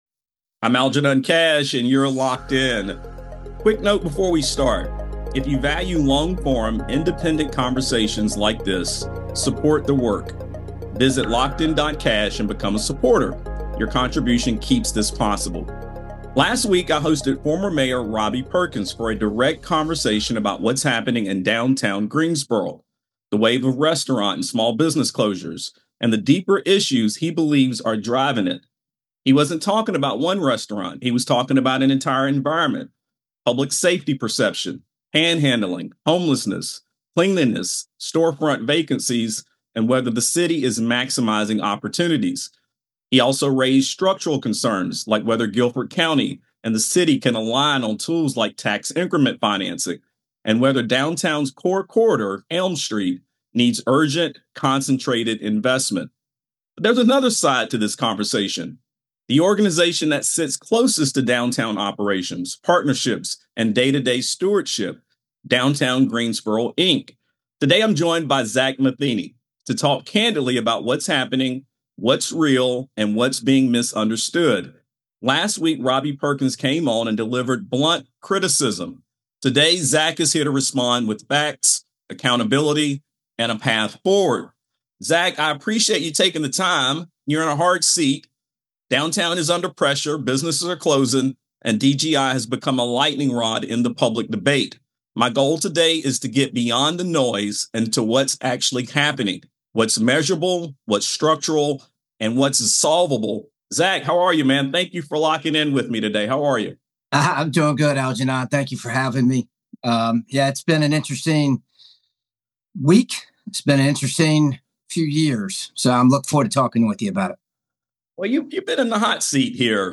A grounded, necessary conversation about focus, leadership, and what it will take to stabilize and revitalize downtown Greensboro.